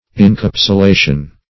Search Result for " incapsulation" : The Collaborative International Dictionary of English v.0.48: Incapsulation \In*cap`su*la"tion\, n. (Physiol.)